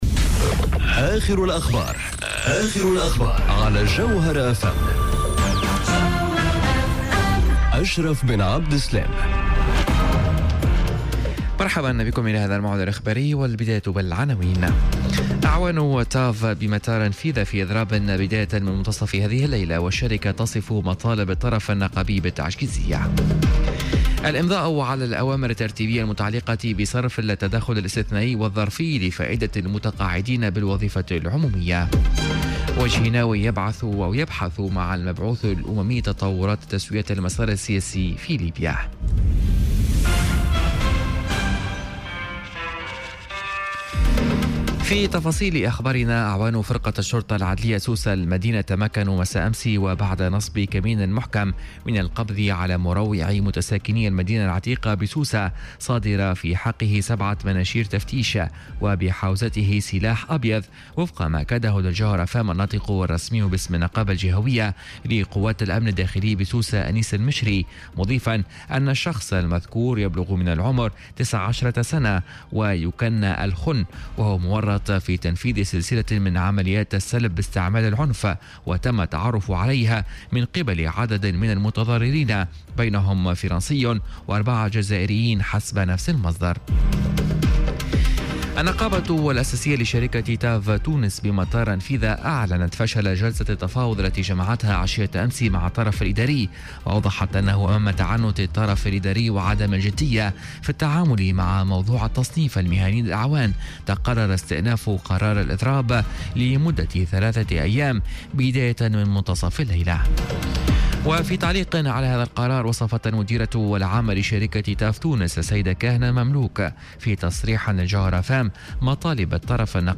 نشرة أخبار منتصف الليل ليوم الثلاثاء 7 أوت 2018